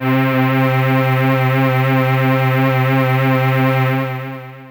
55bd-syn07-c3.aif